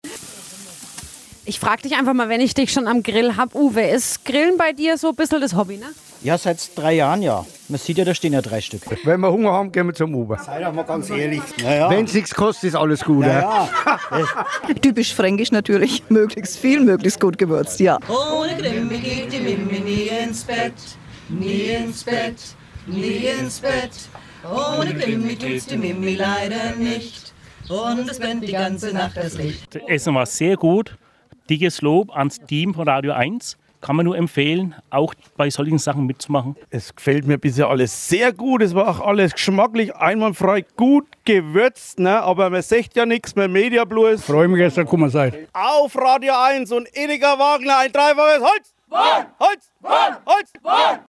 Im Juli 2019 schürten RadioEINS-Moderatoren den Grill an.